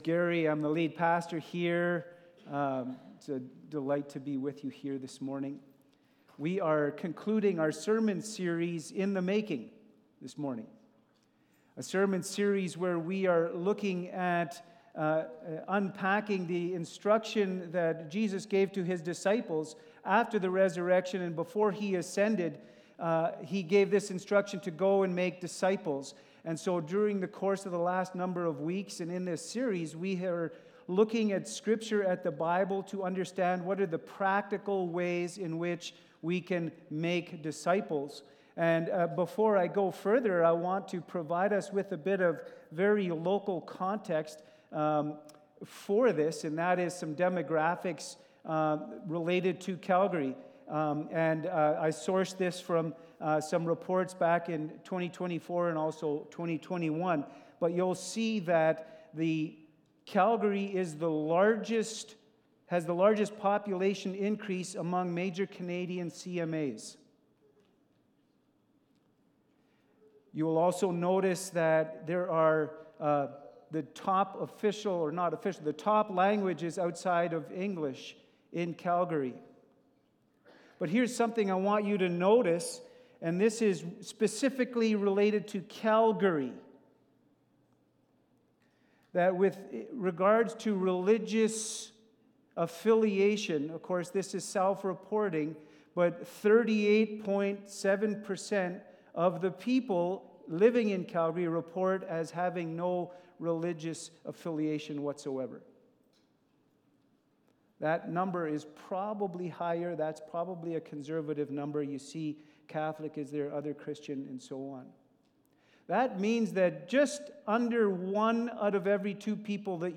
Sermons | Westview Baptist Church